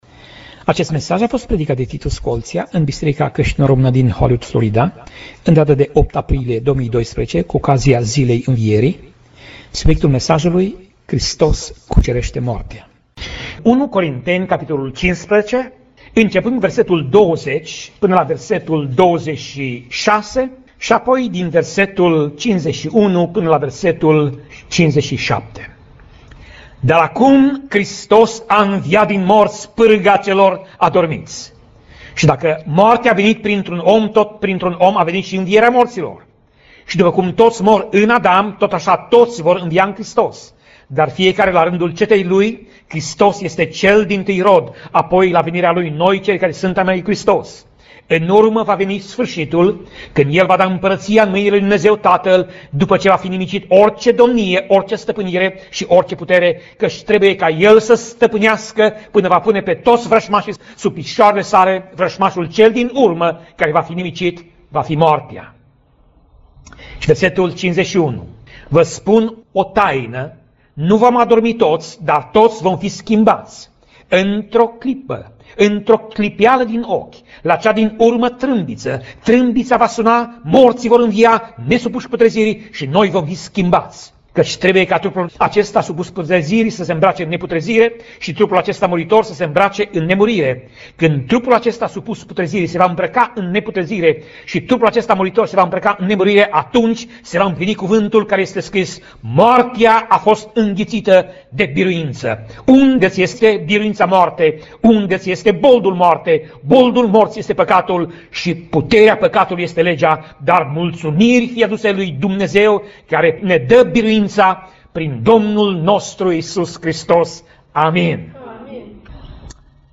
Pasaj Biblie: 1 Corinteni 15:20 - 1 Corinteni 15:26 Tip Mesaj: Predica